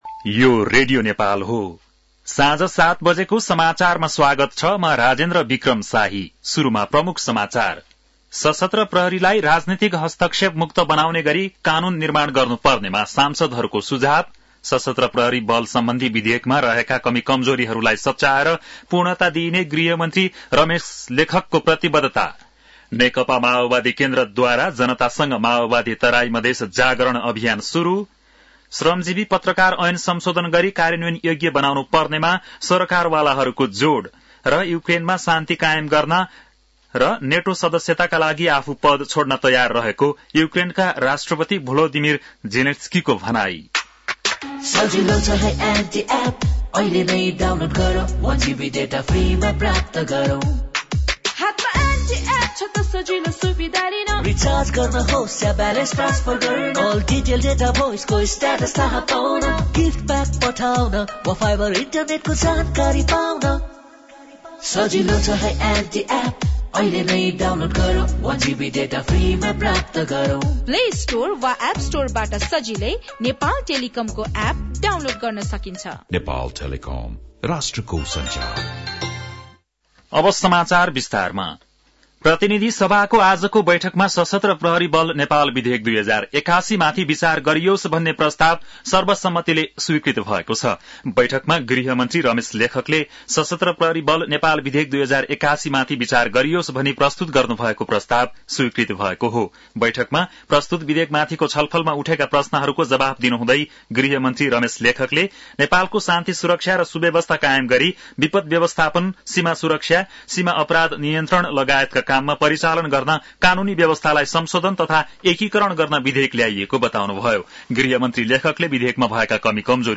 बेलुकी ७ बजेको नेपाली समाचार : १३ फागुन , २०८१
7-pm-nepali-news-11-12.mp3